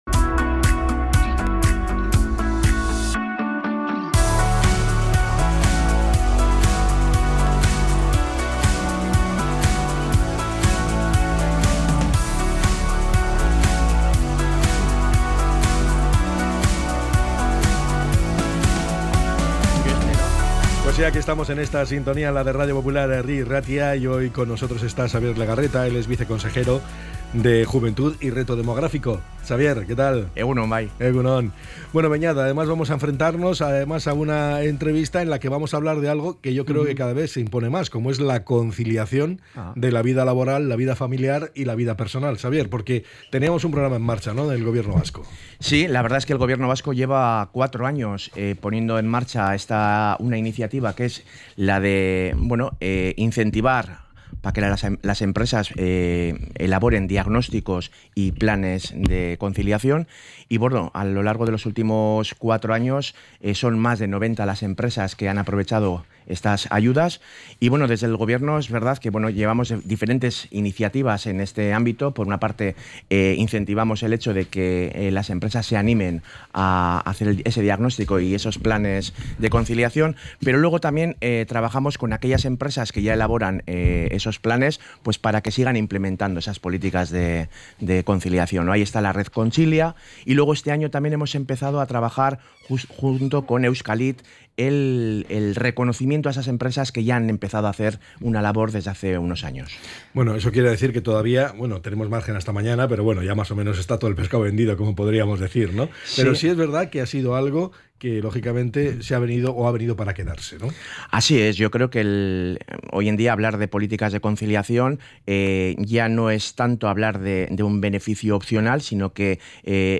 ENTREV.-XAVIER-LEGARRETA.mp3